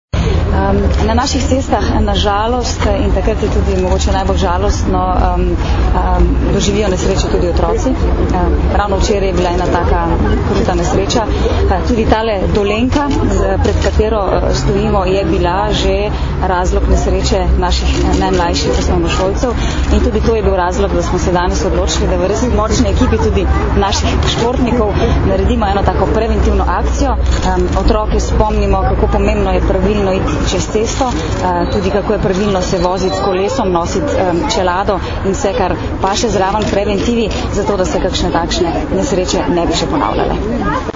Ministrica za notranje zadeve Katarina Kresal in namestnica generalnega direktorja policije mag. Tatjana Bobnar sta danes, 5. aprila, skupaj z učenci OŠ Oskarja Kovačiča, vrhunskima športnikoma Tino Maze in Dejanom Zavcem ter ljubljanskimi policisti opozorili na varnost otrok v prometu.
Zvočni posnetek izjave Katarine Kresal (mp3)